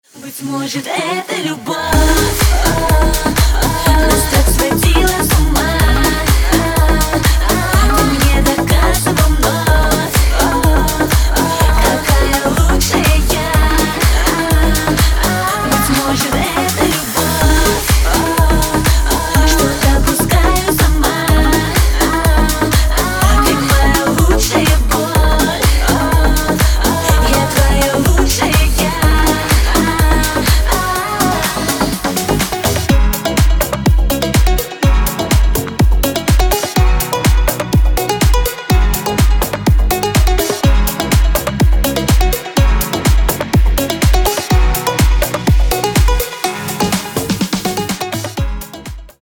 • Качество: 320, Stereo
громкие
женский вокал
dance
Electronic
EDM
Club House
электронная музыка